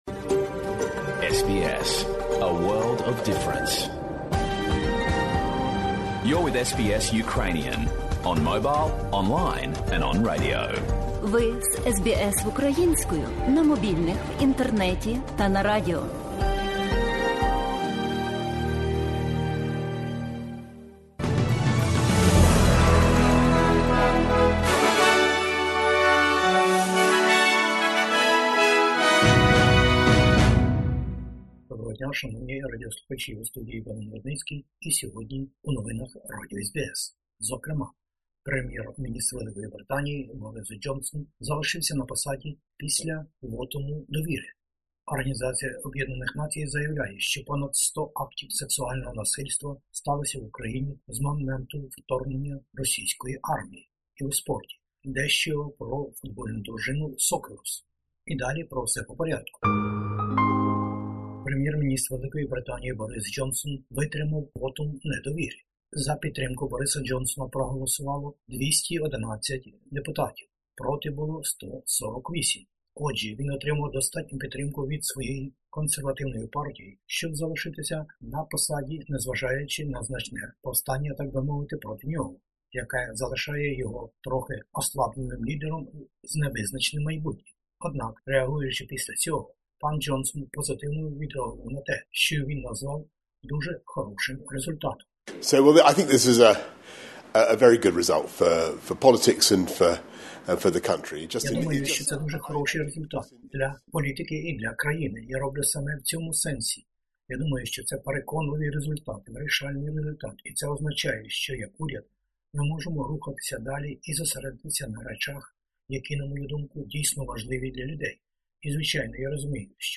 Бюлетень SBS новин українською мовою. Прем'єр-міністр Великої Британії залишився на посаді опісля голосування у парламенті за вотум недовіри.